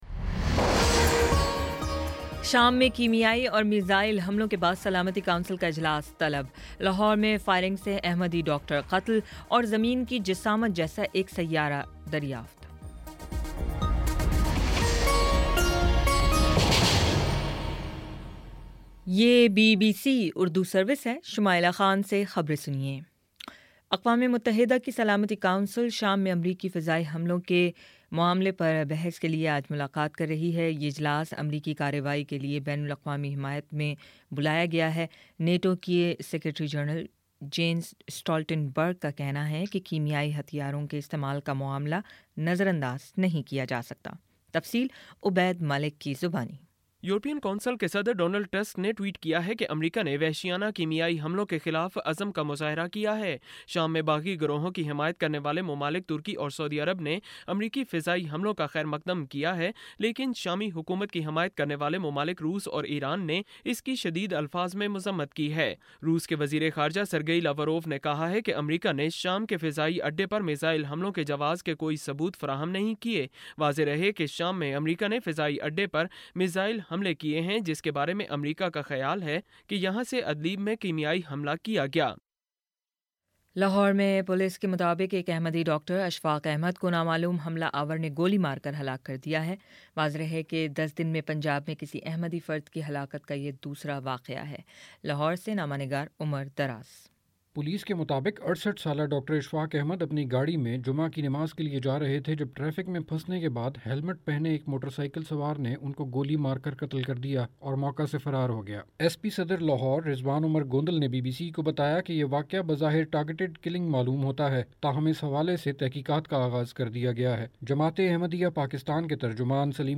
اپریل 07 : شام سات بجے کا نیوز بُلیٹن